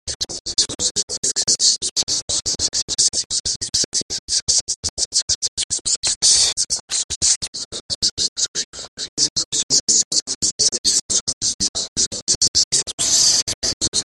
One is the plain kind which he uses when speaking in interviews:
Here are the plain [s] sounds from the interview:
eminem_speech_s.mp3